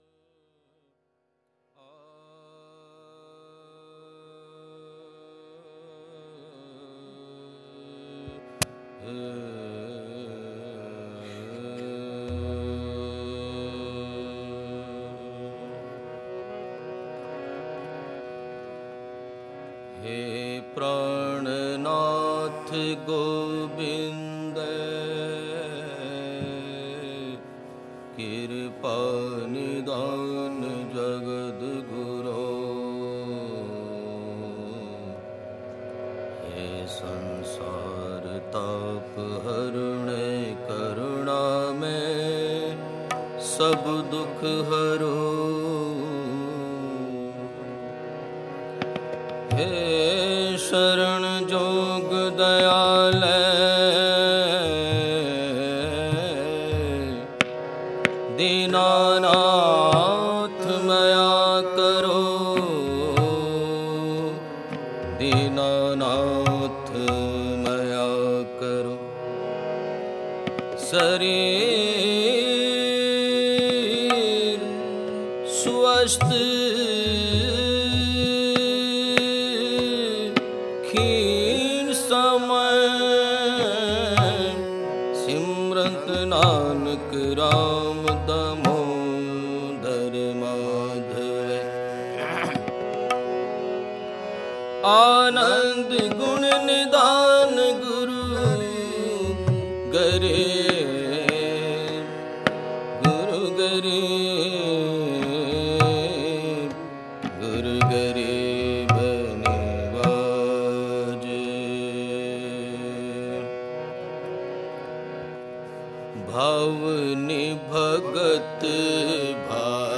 Genre: Shabad Gurbani Kirtan Album Info